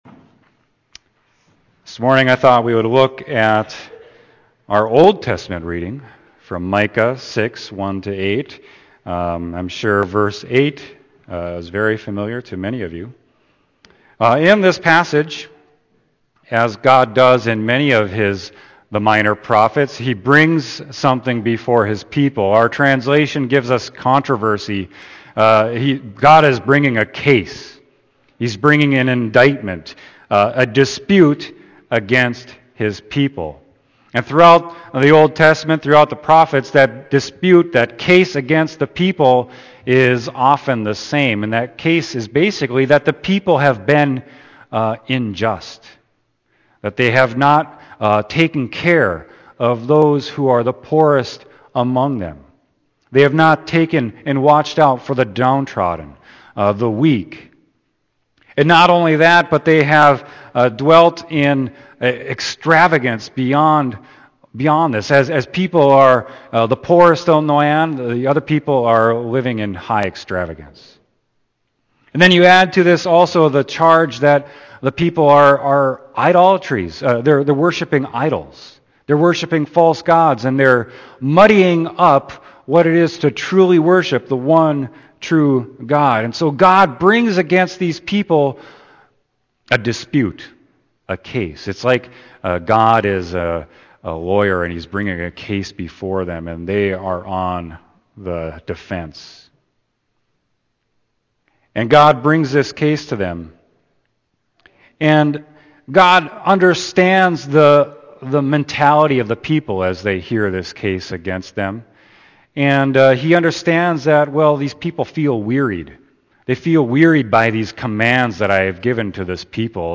Sermon: Micah 6.1-8